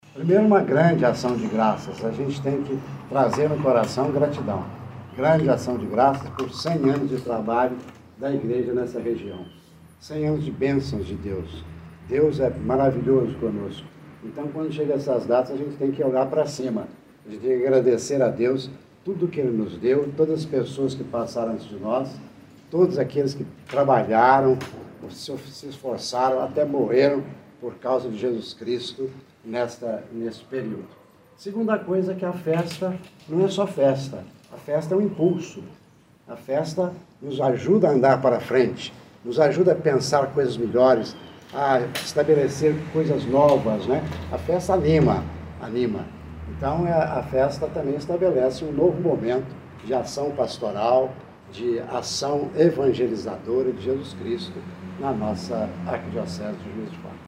O arcebispo metropolitano, Dom Gil Antônio Moreira, fala sobre a importância da data.